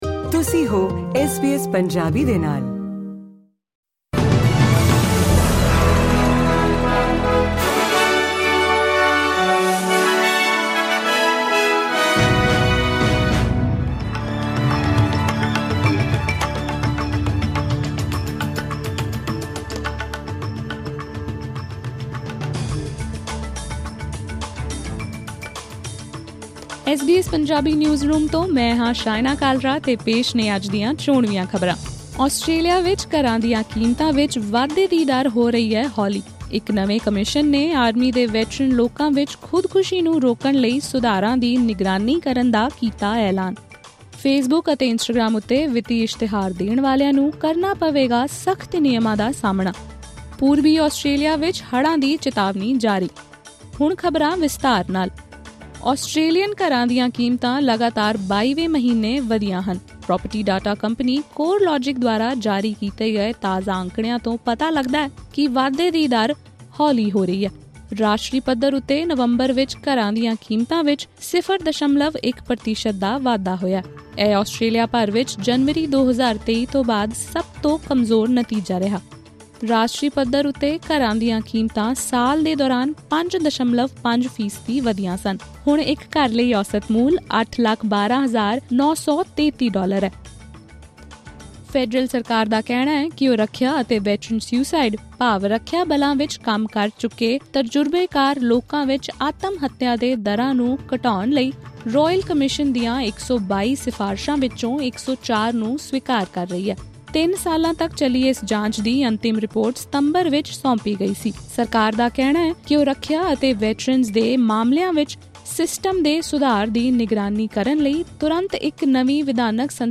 ਐਸ ਬੀ ਐਸ ਪੰਜਾਬੀ ਨਿਊਜ਼ਰੂਮ ਤੋਂ ਆਸਟ੍ਰੇਲੀਆ ਦੀਆਂ ਅੱਜ ਦੀਆਂ ਕੁੱਝ ਪ੍ਰਮੁੱਖ ਖ਼ਬਰਾਂ ਸੁਣੋ ਇਸ ਪੌਡਕਾਸਟ ਰਾਹੀਂ: LISTEN TO ਆਸਟ੍ਰੇਲੀਆ ਦੇ ਘਰਾਂ ਦੀਆਂ ਕੀਮਤਾਂ ਵਿੱਚ ਚੱਲ ਰਹੀ ਵਾਧੇ ਦੀ ਦਰ ਹੋਈ ਕੁਝ ਹੌਲੀ SBS Punjabi 02/12/2024 04:04 Play ਸਾਡੇ ਸਾਰੇ ਪੌਡਕਾਸਟ ਇਸ ਲਿੰਕ ਰਾਹੀਂ ਸੁਣੇ ਜਾ ਸਕਦੇ ਹਨ।